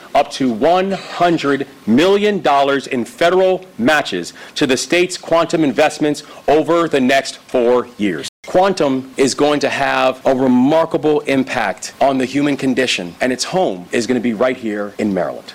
Moore spoke about the federal partnership in the project…